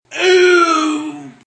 Tags: Jeapordy Game Show Sounds Effect